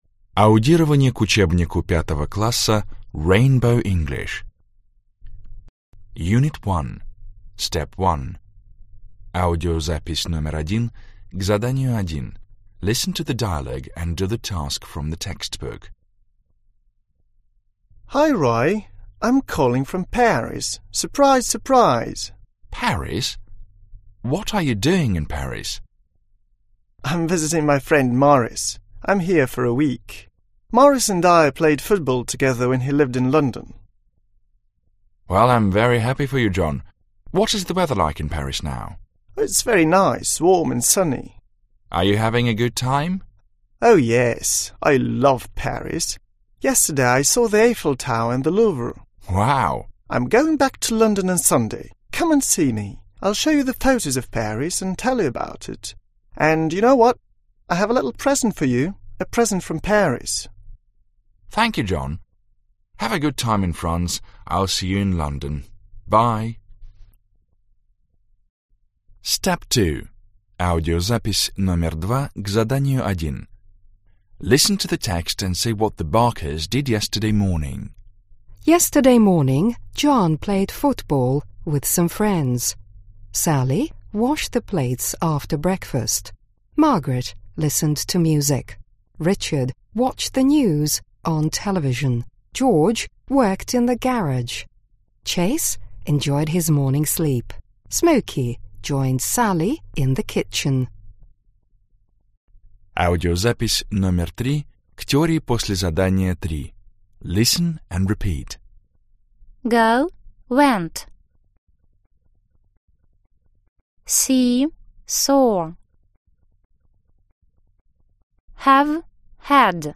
Аудиокнига Английский язык. 5 класс. Аудиоприложение к учебнику часть 1 | Библиотека аудиокниг